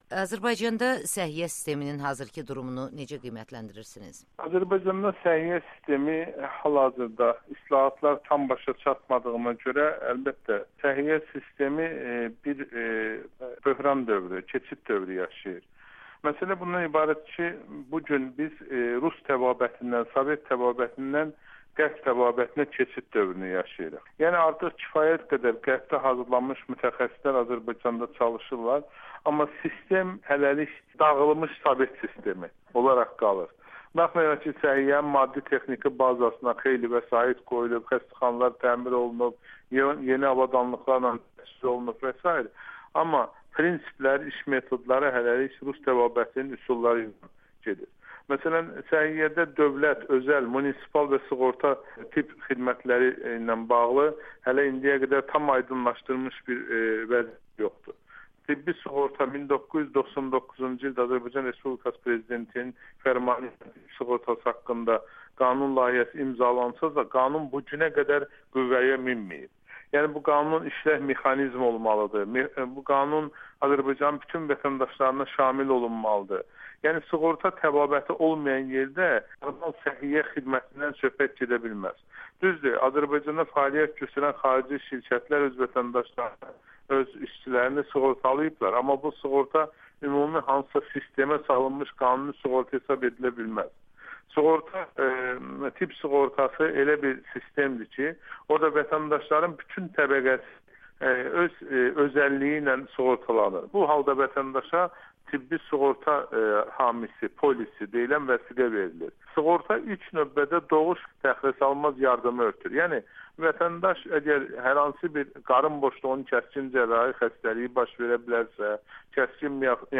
müsahibəsi